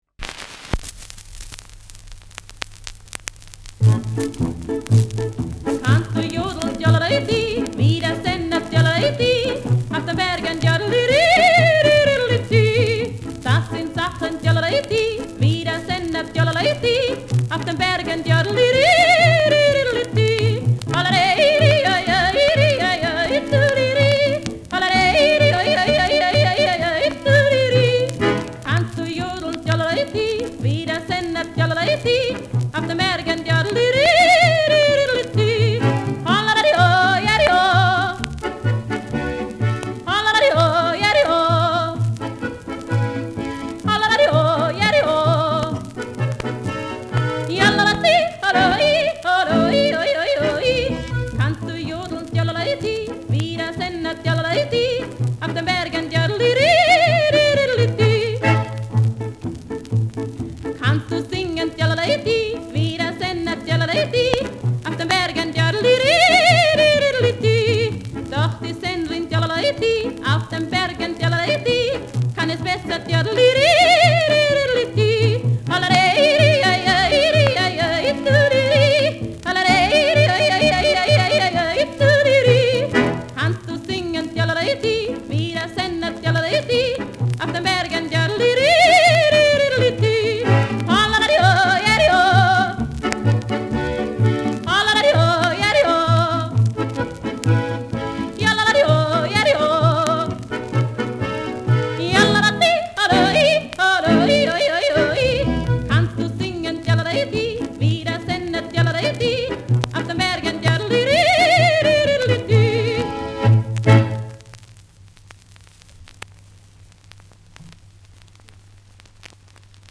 Schellackplatte
Durchgehend stärkeres Knacken : Leicht abgespielt